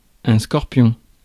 Ääntäminen
Ääntäminen France: IPA: [skɔʁ.pjɔ̃] Haettu sana löytyi näillä lähdekielillä: ranska Käännös Konteksti Substantiivit 1. scorpion eläintiede 2. scorpio Suku: m .